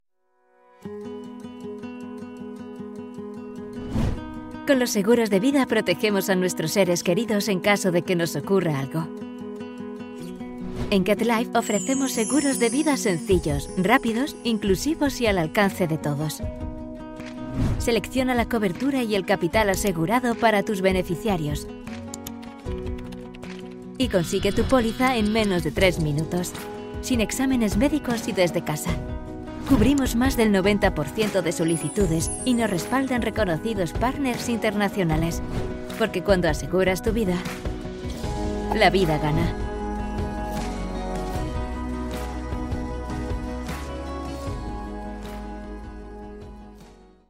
Comercial, Accesible, Versátil, Cálida, Suave
Corporativo